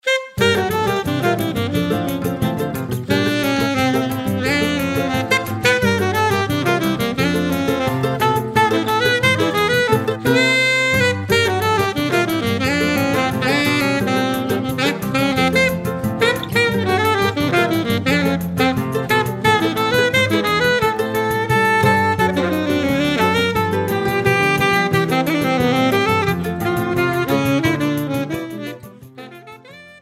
alto saxophone